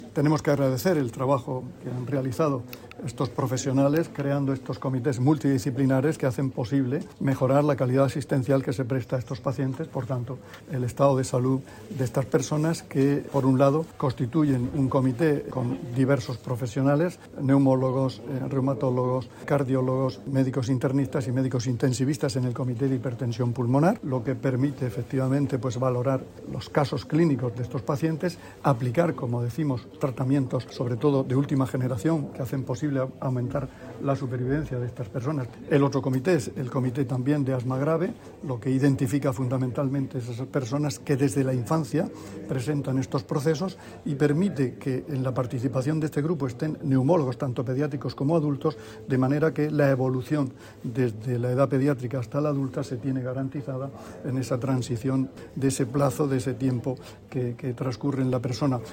Sonido/ Declaraciones del consejero de Salud, Juan José Pedreño, sobre los comités de hipertensión arterial pulmonar y asma grave del hospital Rafael Méndez.